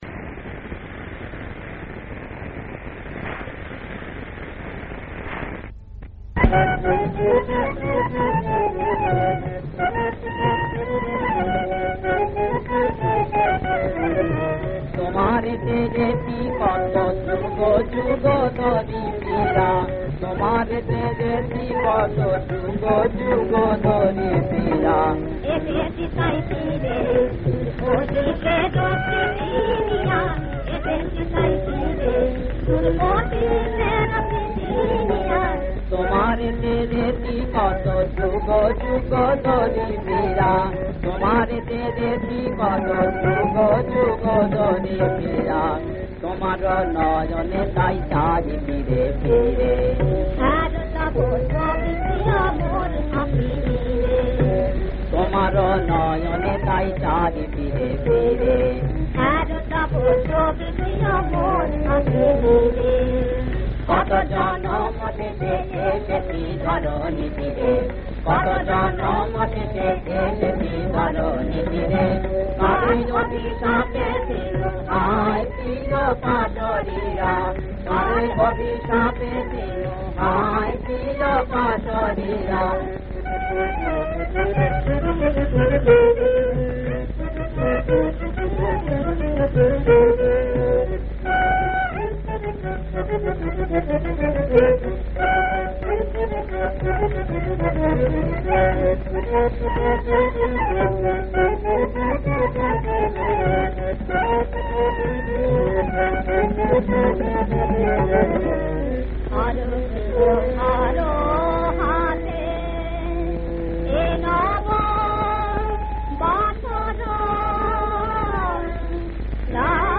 • তাল: তালফেরতা (কাহারবা/ দাদরা)
• গ্রহস্বর: পা